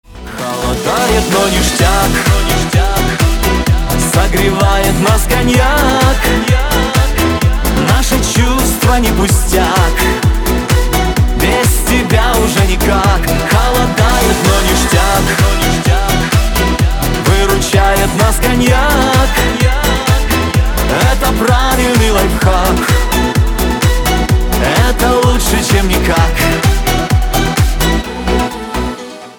Категория: Шансон рингтоны